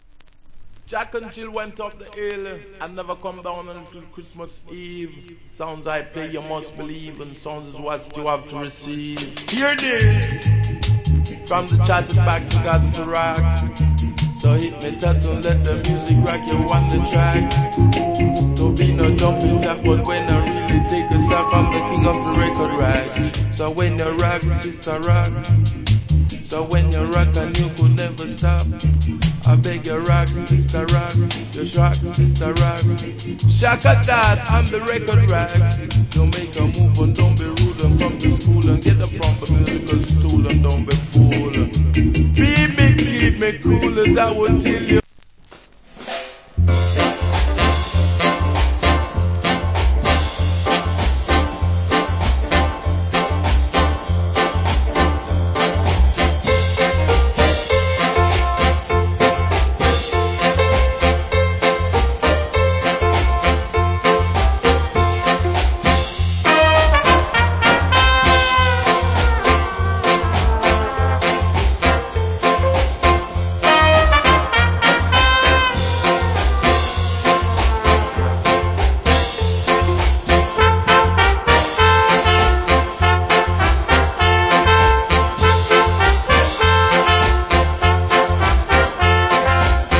Good DJ.